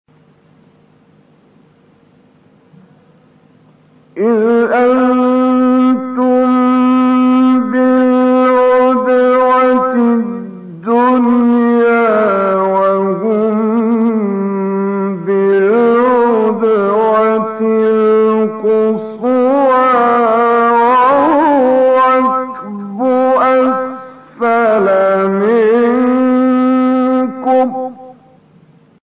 تلاوت در کانال‌های قرآنی/
گروه شبکه اجتماعی: فرازهای صوتی از کامل یوسف البهتیمی که در مقام بیات اجرا شده است، می‌شنوید.